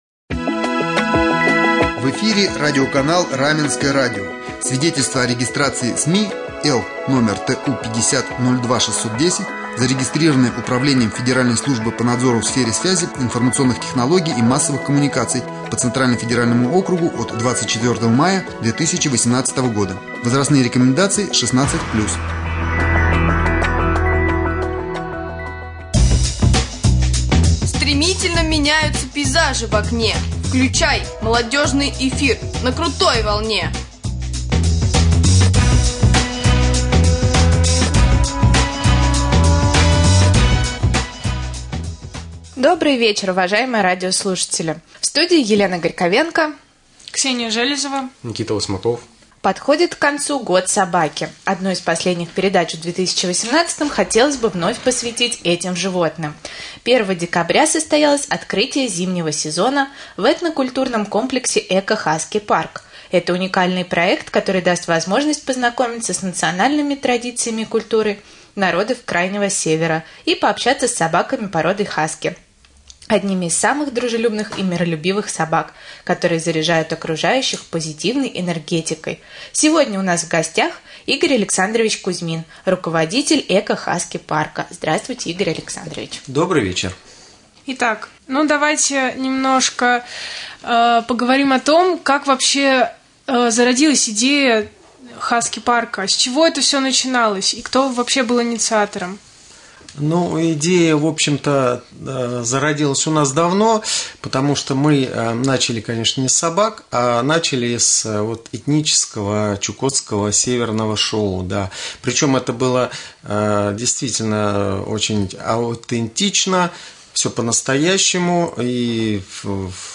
стал гостем эфира